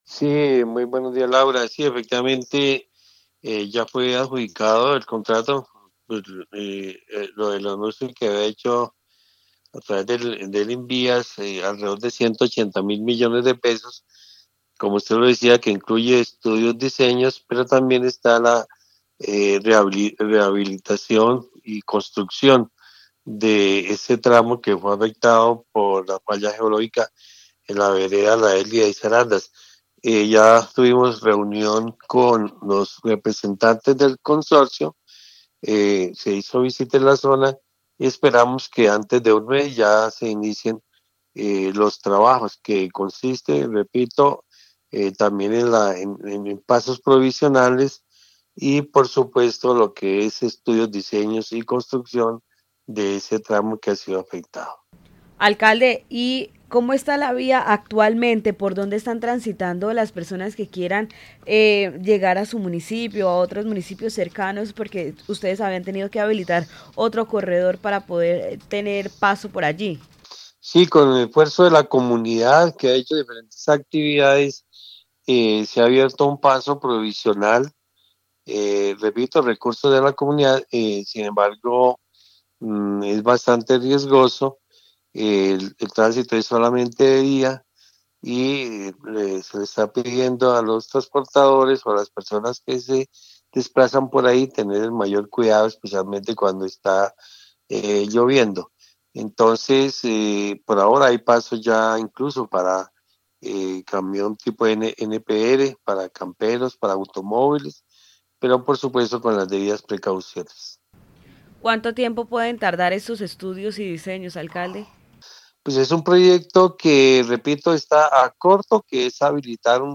Orlando Ariza, alcalde de Vélez